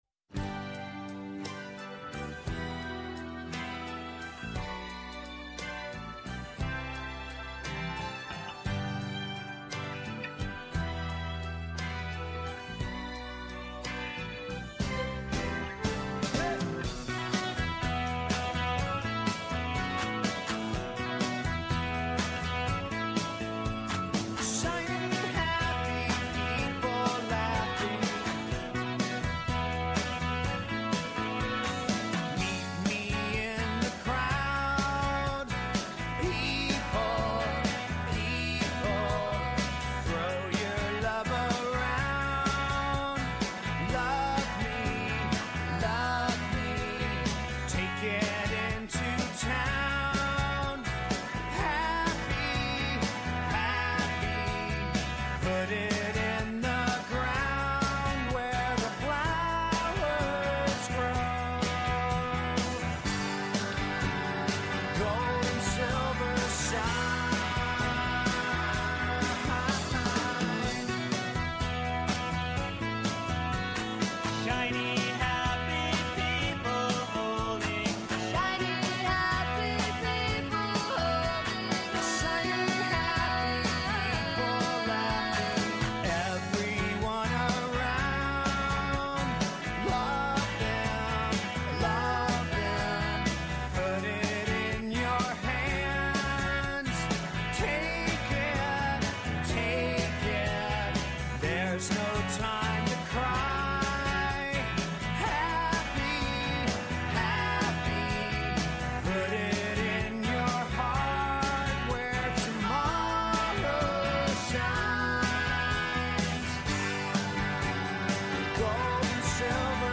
Αποχαιρετά την ημέρα που τελειώνει, ανιχνεύοντας αυτή που έρχεται. Διεθνή και εγχώρια επικαιρότητα, πολιτισμός, πρόσωπα, ιστορίες αλλά και αποτύπωση της ατζέντας της επόμενης ημέρας συνθέτουν ένα διαφορετικό είδος μαγκαζίνου με στόχο να εντοπίσουμε το θέμα της επόμενης ημέρας. Τί μας ξημερώνει αύριο; Ποιο θα είναι το γεγονός που θα κυριαρχήσει με το πρώτο φως του ηλίου; Τί θα απασχολήσει στη δημόσια σφαίρα; Ο επίλογος ενός 24ωρ